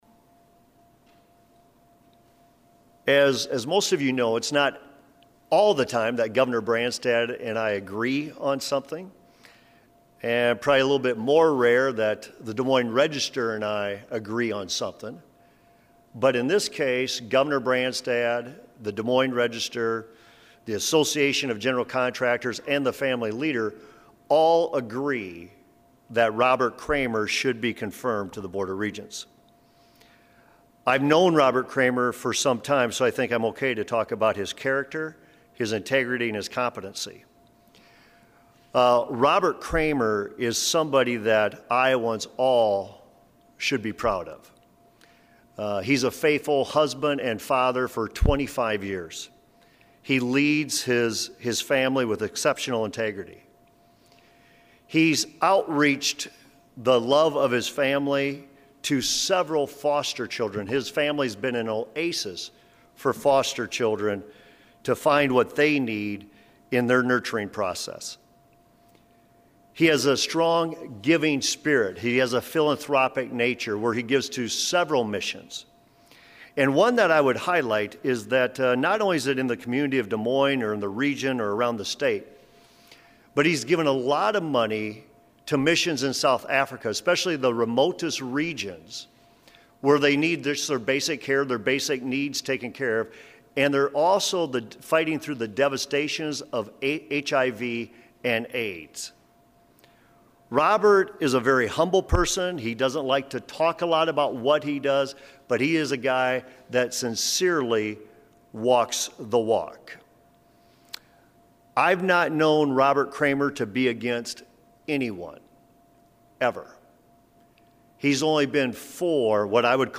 AUDIO Vander Plaats news conference 12:00.